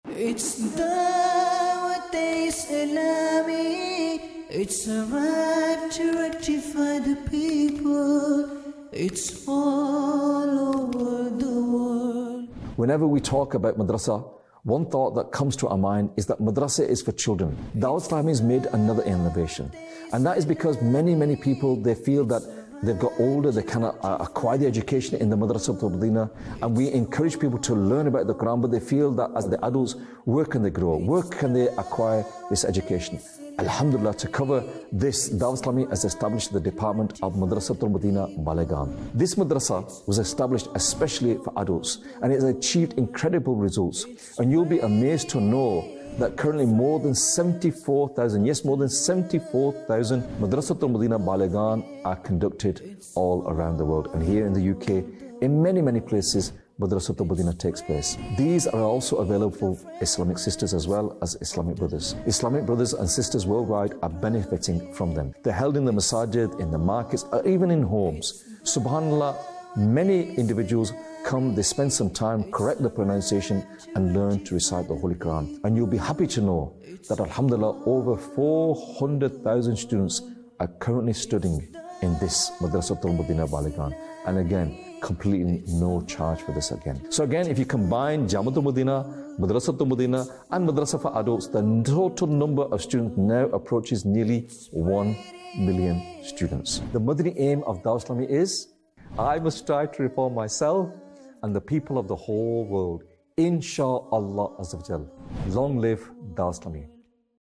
Documentary 2025